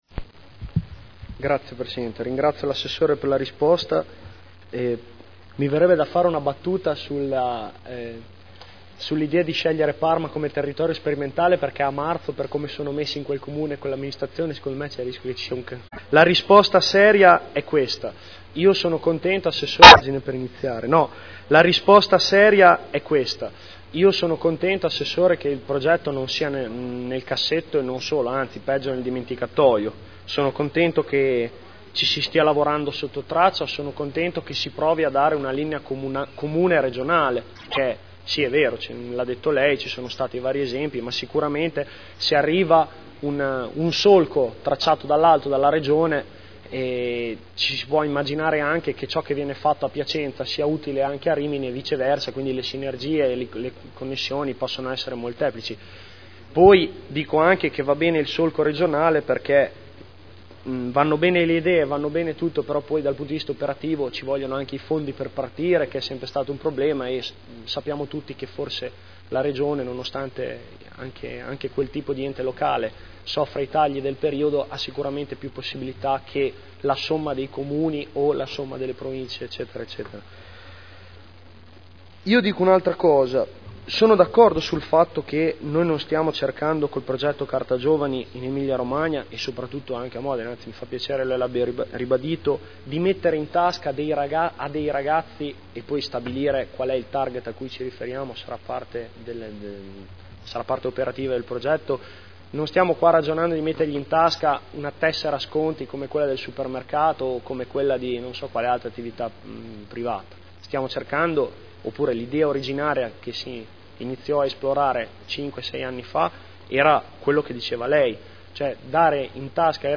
Seduta del 4 luglio 2011. Interrogazione del consigliere Guerzoni (P.D.) avente per oggetto: “Progetto Carta Giovani” Replica